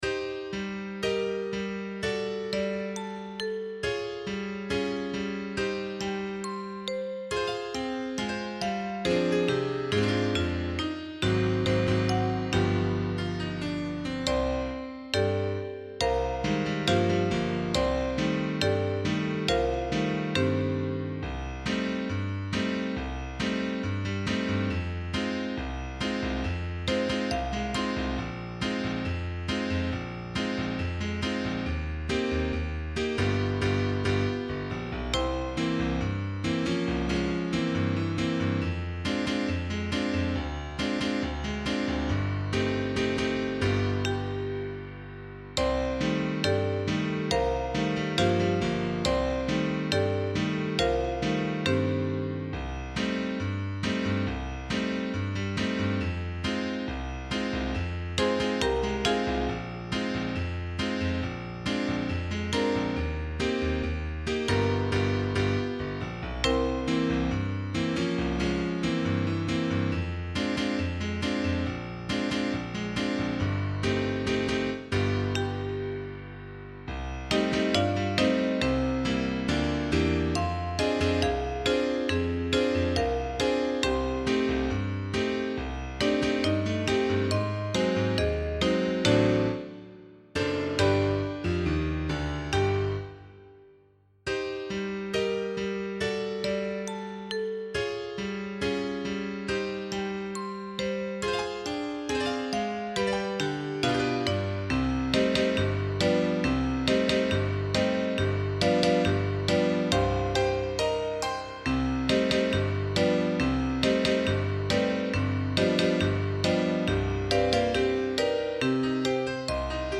04_Accompaniment_Only_Can_You_Hear_the_Christmas_Bells.mp3